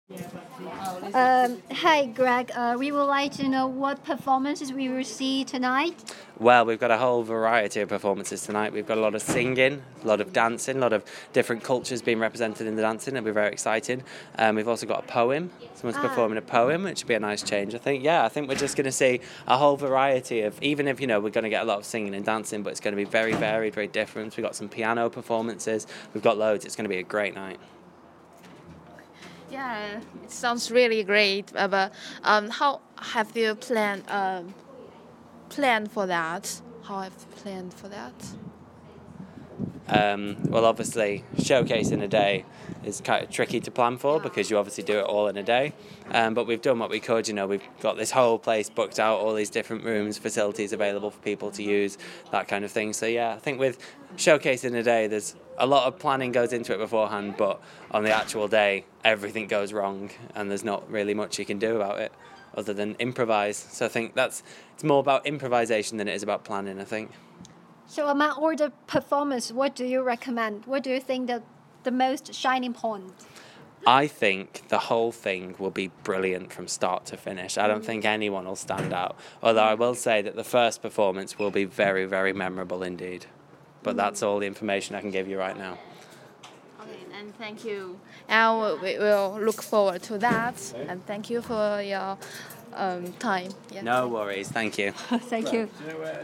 performance management interview——CCTV